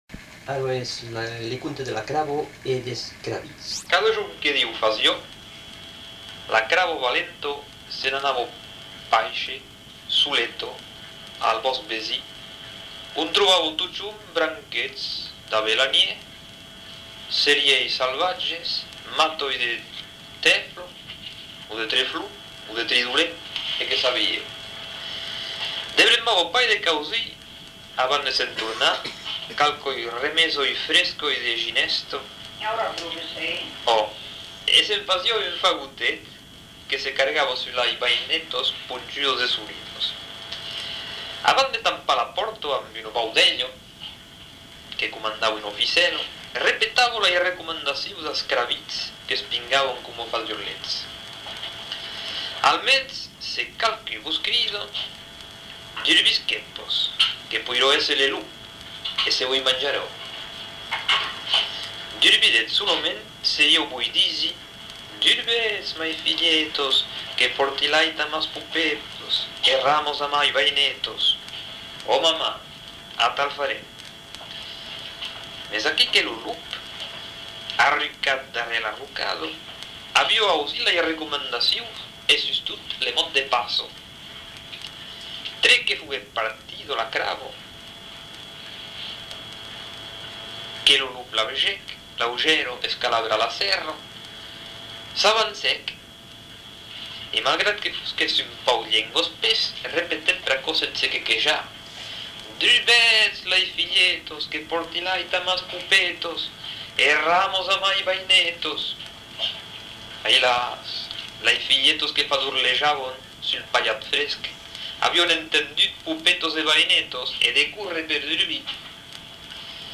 Genre : conte-légende-récit
Effectif : 1
Type de voix : voix d'homme
Production du son : parlé
Langue : occitan (languedocien)